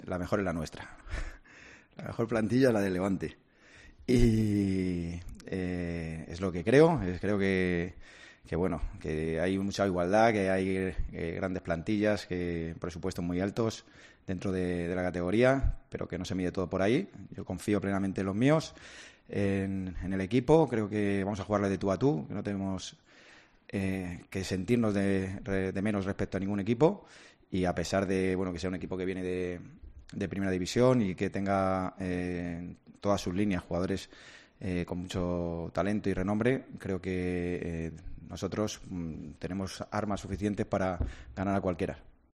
El entrenador del Levante UD, Javi Calleja, aseguró este jueves en la víspera de enfrentarse en Liga al Espanyol que la mejor plantilla de la categoría es la que él dirige y destacó que competirán “de tú a tú” ante el cuadro catalán.